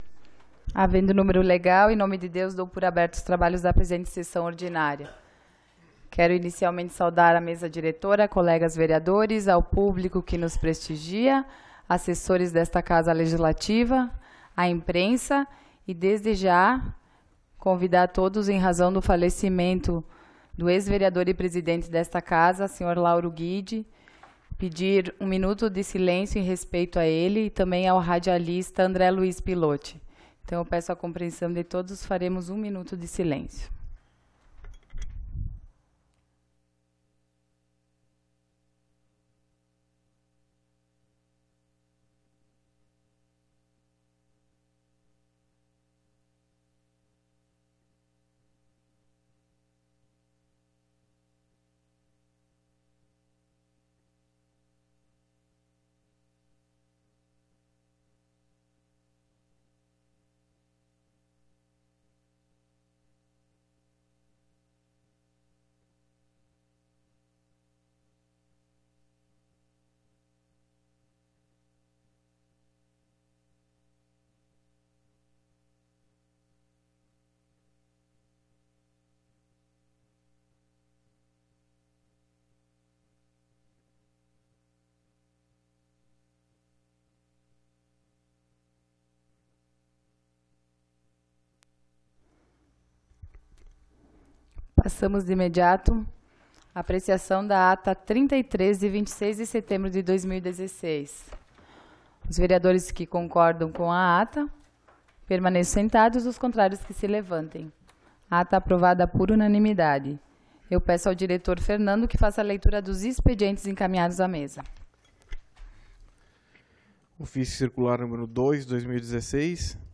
Sessão Ordinária do dia 03 de Outubro de 2016